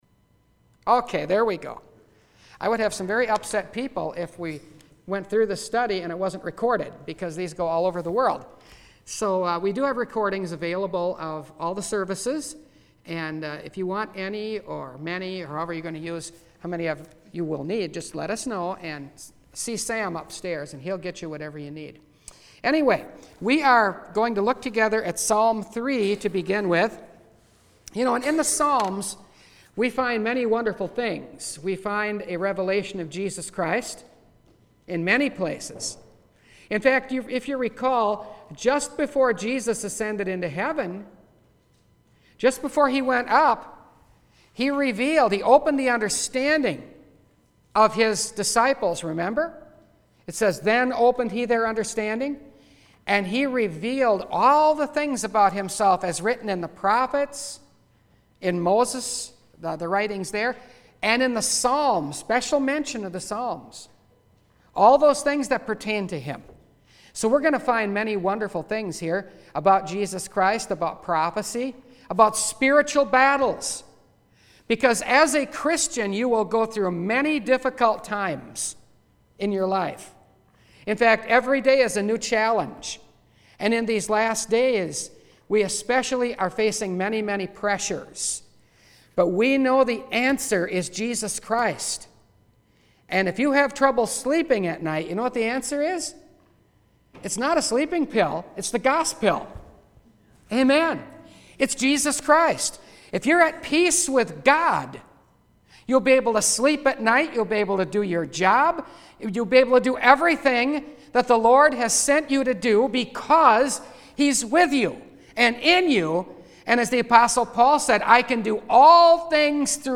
For The Lord Sustained Me – Last Trumpet Ministries – Truth Tabernacle – Sermon Library